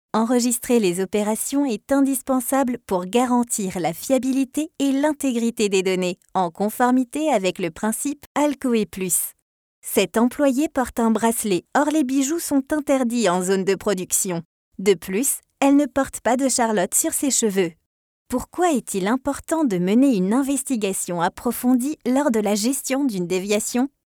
Female
French (European), French (Parisienne)
My voice is young and medium.
E-Learning
Voix Naturelle, PoséE